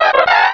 Cri de Muciole dans Pokémon Rubis et Saphir.